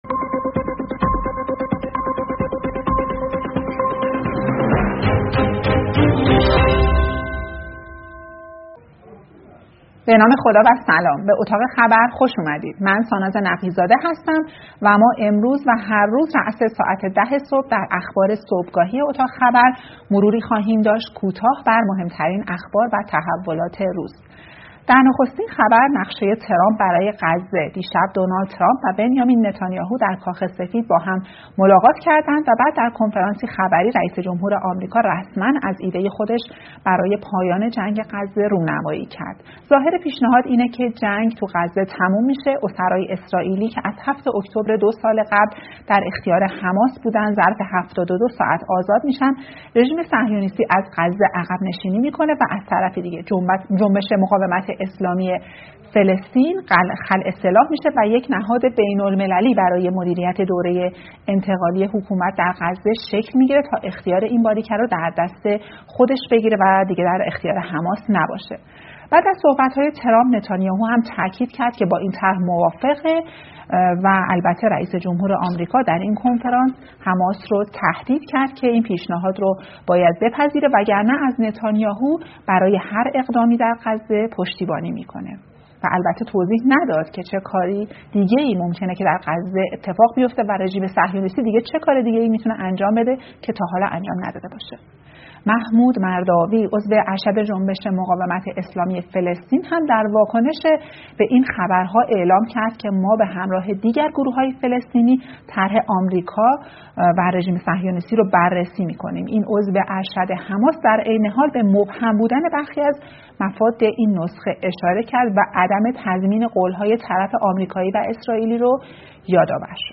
اخبار صبحگاهی سه‌شنبه ۸ مهرماهِ اتاق خبر ایرنا